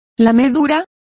Complete with pronunciation of the translation of licks.